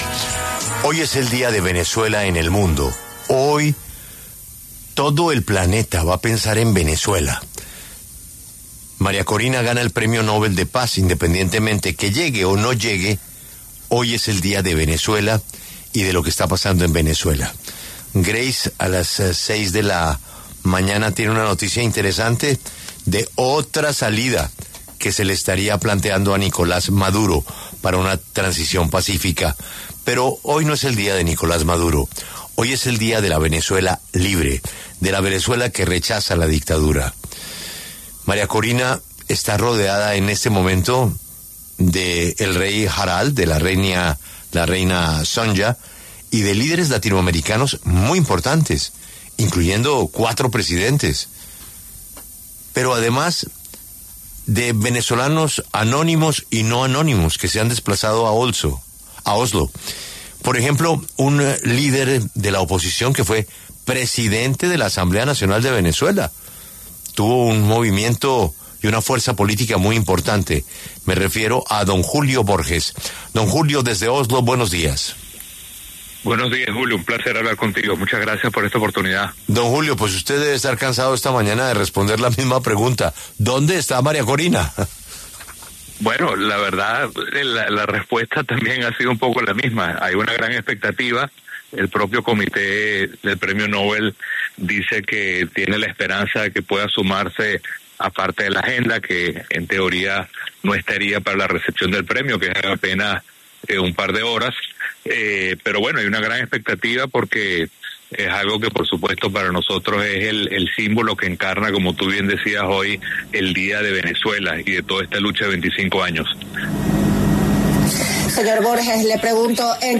Por esta razón, Julio Borges, expresidente de la Asamblea Nacional de Venezuela, y quien está en Oslo, habló en los micrófonos de La W, con Julio Sánchez Cristo, para referirse al tema.